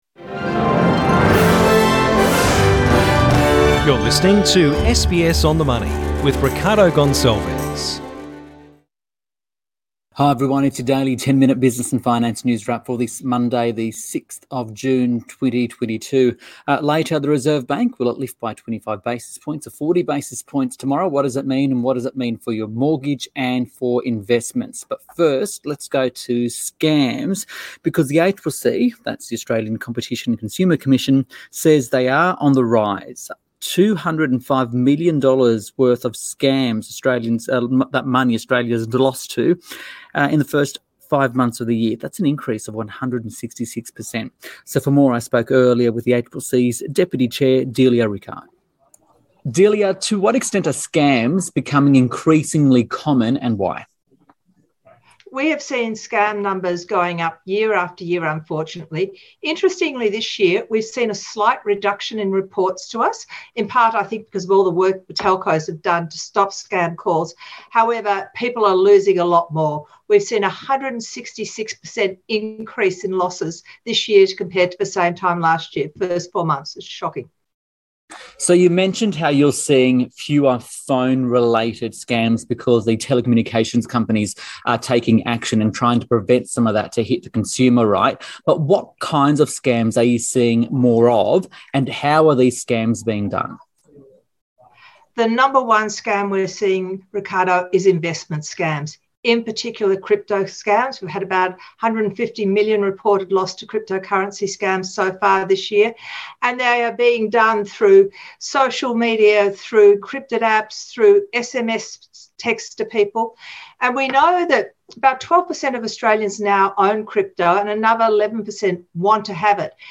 speaks with ACCC Deputy Chair Delia Rickard